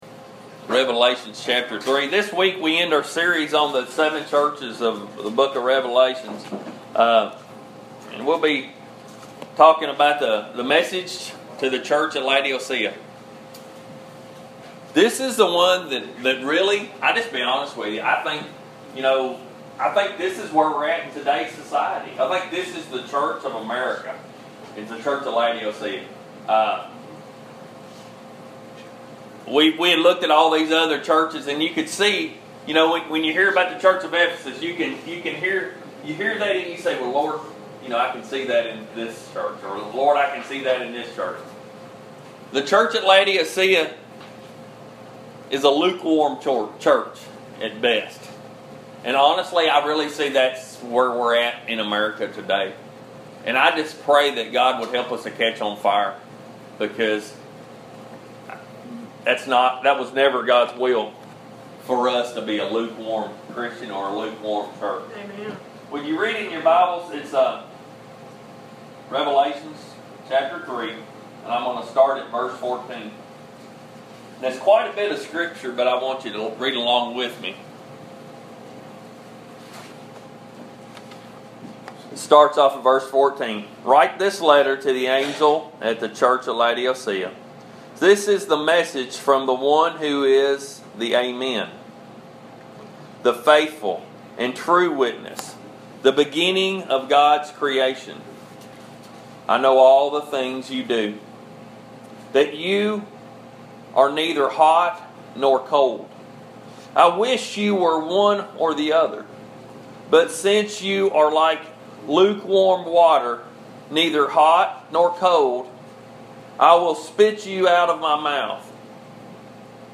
The Fellowship of Lighthouse Ministries Sermons